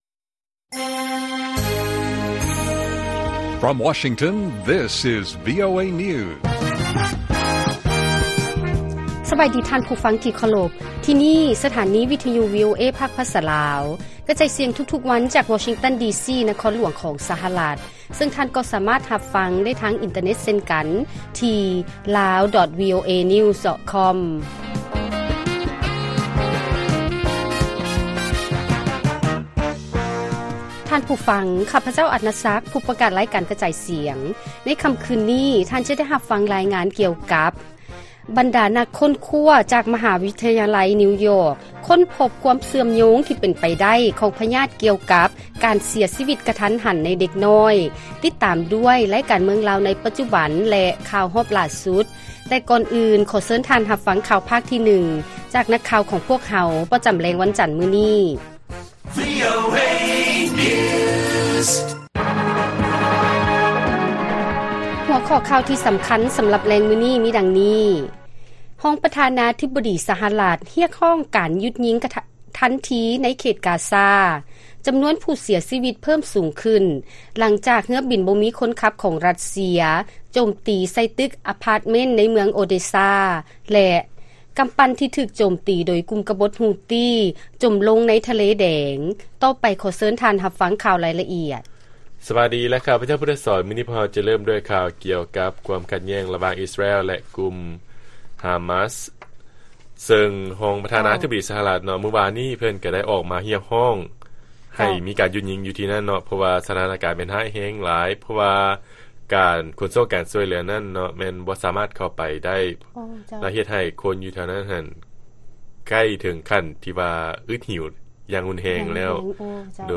ລາຍການກະຈາຍສຽງຂອງວີໂອເອ ລາວ: ນັກຄົ້ນຄວ້າ ຈາກມະຫາວິທະຍາໄລນິວຢອກ ຄົ້ນພົບຄວາມເຊື່ອມໂຍງທີ່ເປັນໄປໄດ້ກັບພະຍາດກ່ຽວກັບ ການເສຍຊີວິດກະທັນຫັນໃນເດັກນ້ອຍ
ສຳລັບແລງມື້ນີ້ ທ່ານຈະໄດ້ຮັບຟັງລາຍງານ ກ່ຽວກັບ ບັນດານັກຄົ້ນຄວ້າ ຈາກມະຫາວິທະຍາໄລ ນິວຢອກ ຄົ້ນພົບຄວາມເຊື່ອມໂຍງທີ່ເປັນໄປໄດ້ ກັບພະຍາດກ່ຽວກັບ ການເສຍຊີວິດກະທັນຫັນໃນເດັກນ້ອຍ, ຕິດຕາມດ້ວຍ ລາຍການເມືອງລາວໃນປັດຈຸບັນ, ແລະຂ່າວຮອບຫຼ້າສຸດ.